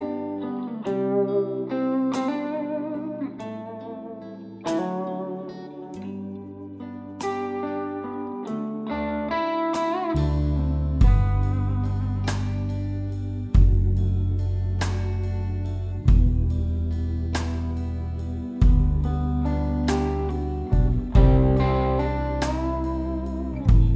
Guitar Solo Cut Down Duets 4:31 Buy £1.50